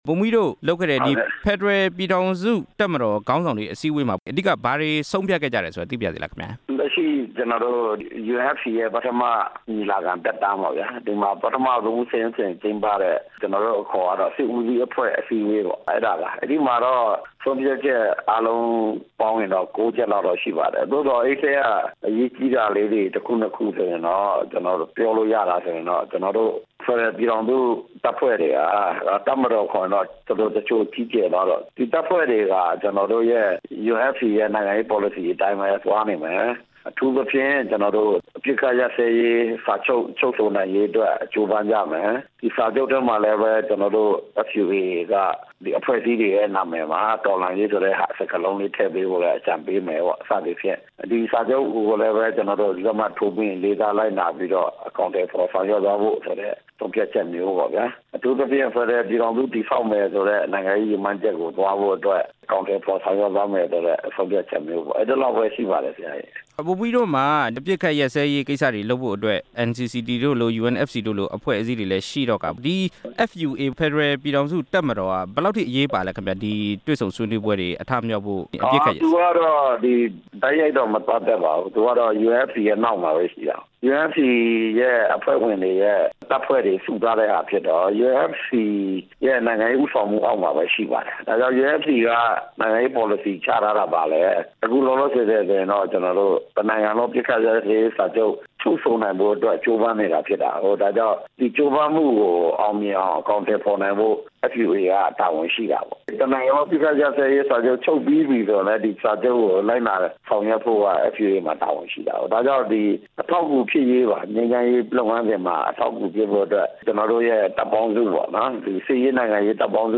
ဖက်ဒရယ် ပြည်ထောင်စု တပ်မတော် ညီလာခံ အကြောင်း မေးမြန်းချက်